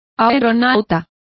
Complete with pronunciation of the translation of balloonists.